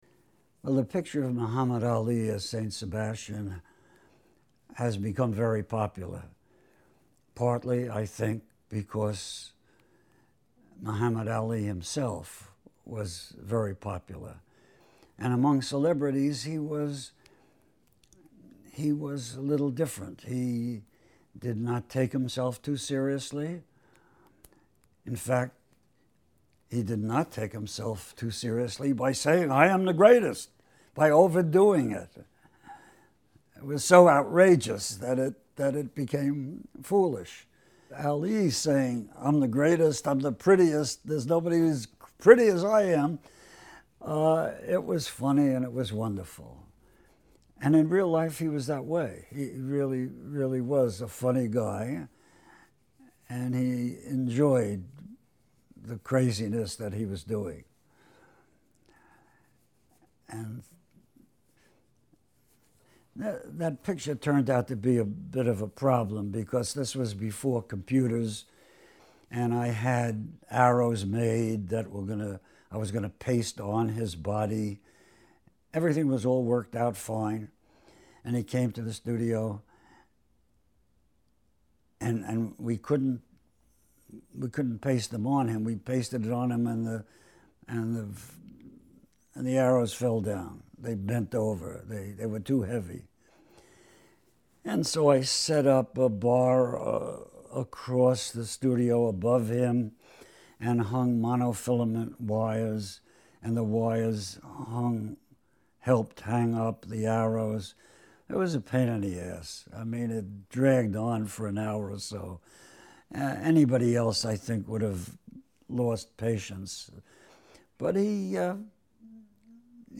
Artist Interview